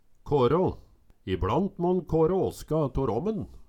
Høyr på uttala Ordklasse: Verb Attende til søk